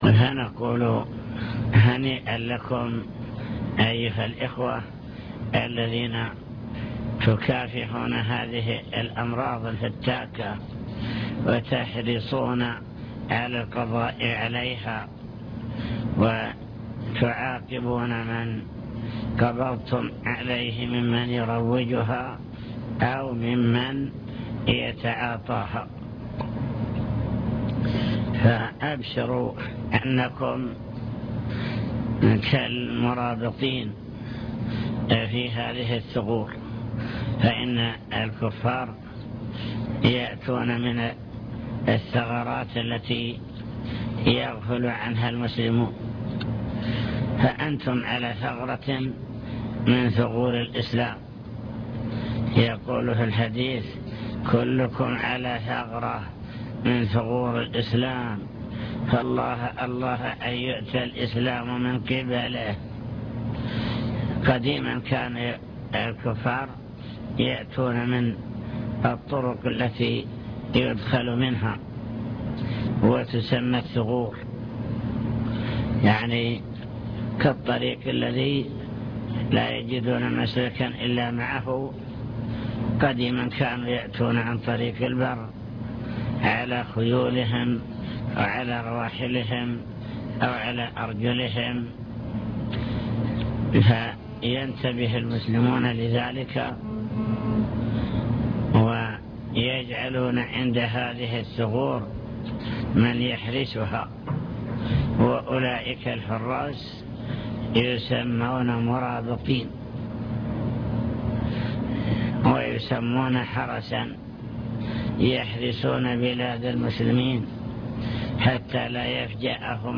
المكتبة الصوتية  تسجيلات - لقاءات  كلمة للعاملين في مكافحة المخدرات أضرار المخدرات